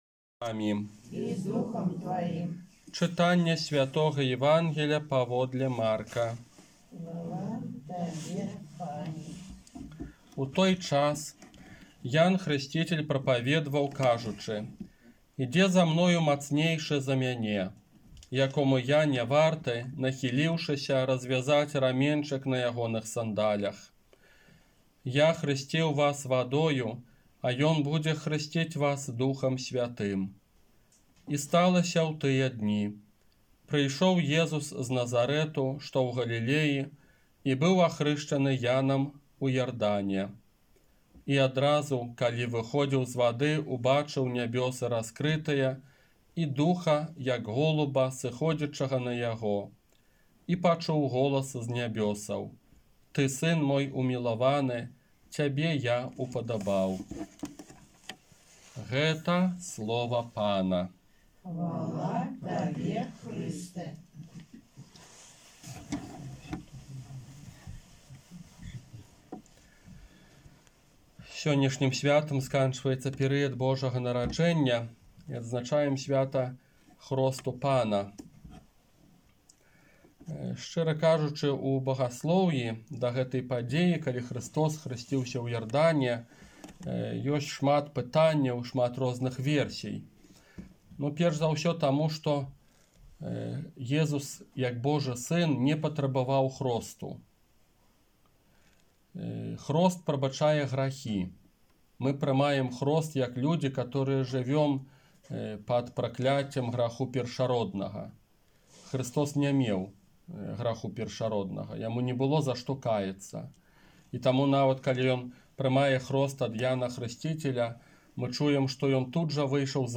ОРША - ПАРАФІЯ СВЯТОГА ЯЗЭПА
Казанне на свята Хрышчэння Пана 10 студзеня 2021 года